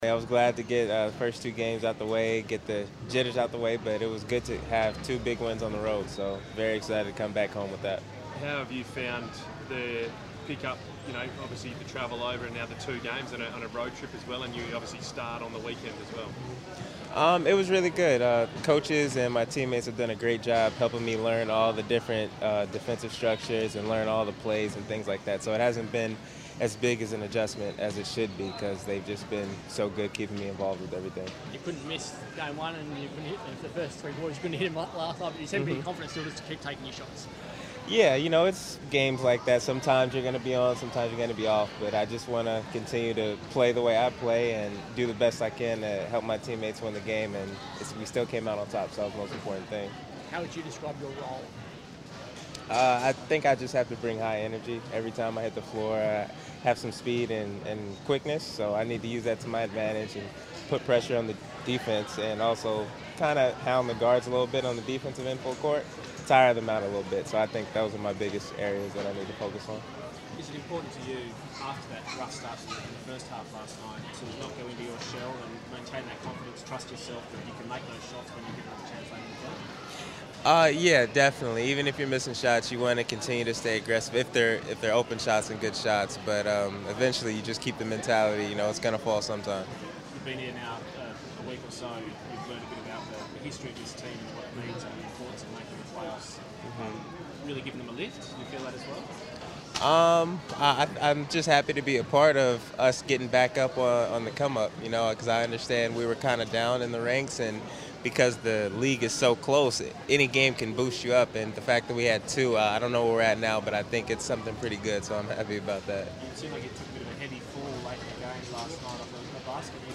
Bryce Cotton press conference - 10 January 2017